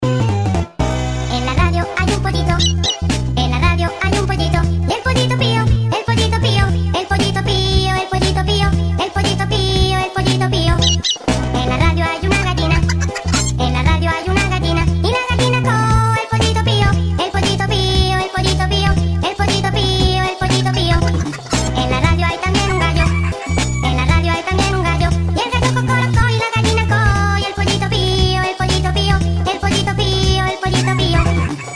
• Качество: 192, Stereo
смешные